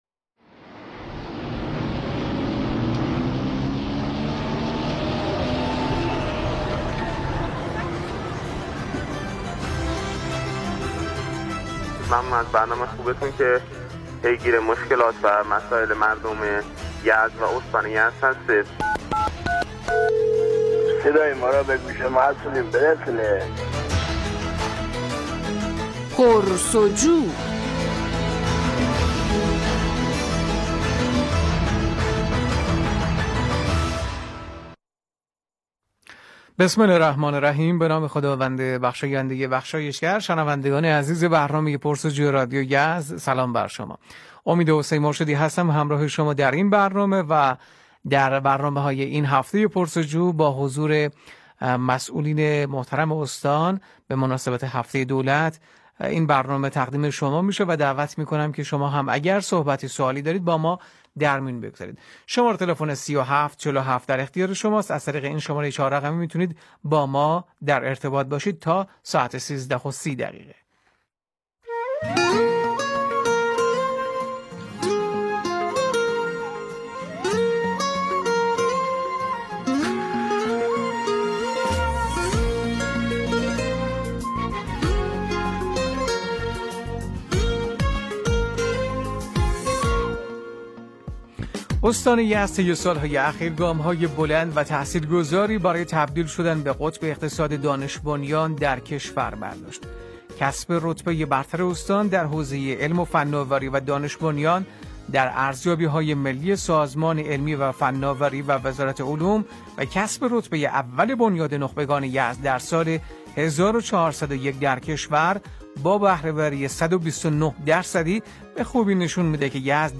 گفتگوی رییس بنیاد نخبگان استان یزد با مردم و نخبگان
به گزارش پایگاه اطلاع رسانی بنیاد نخبگان استان یزد، رییس این بنیاد دیروز در برنامه زنده رادیویی"پرس و جو" حاضر شد و روند فعالیت ها، طرح ها و موفقیت های بنیاد نخبگان استان را تشریح کرد.